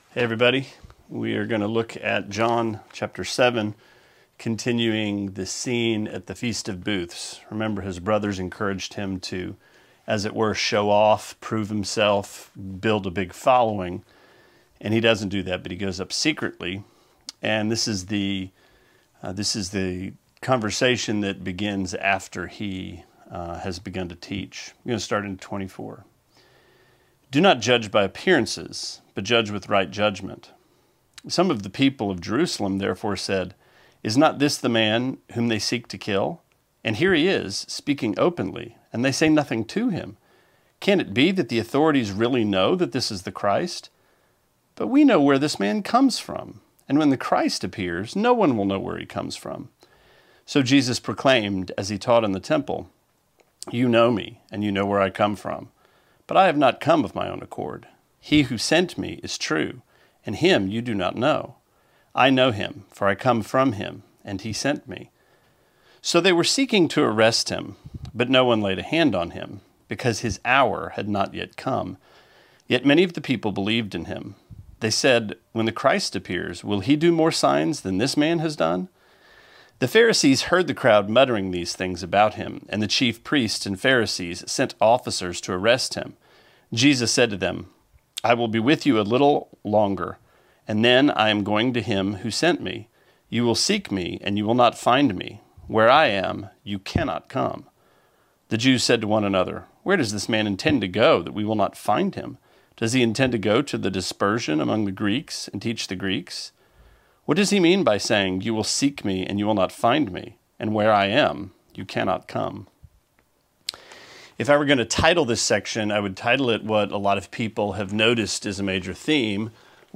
Sermonette 5/10: John 7:25-36: Whence and Whither